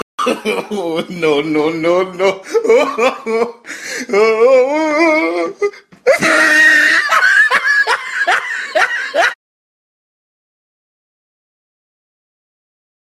Oh No No Laugh Freaky